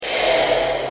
Alien Sound
:)Sounds like an alien Darth Vader
Alien sound_0.mp3